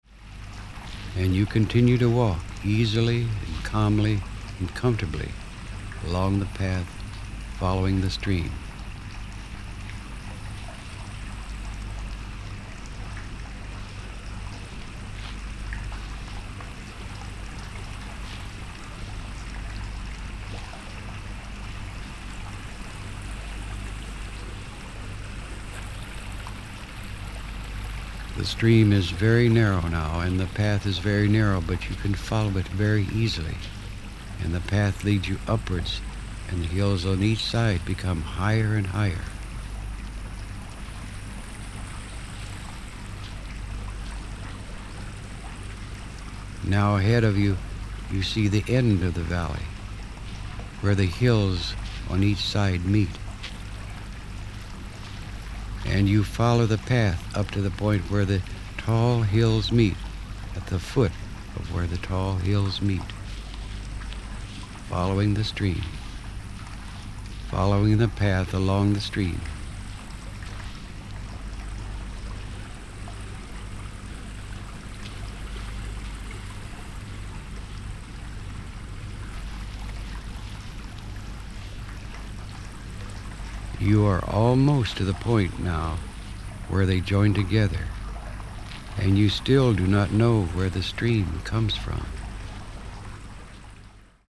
Popis produktu:Báječně uvolňující zvukové vzory Hemi-Sync® podporuje velmi detailní verbální vedení titulu The Visit, které Vás zavede za hranice času, do přítomnosti těch, kteří Vás milují a jež Vám rozumějí.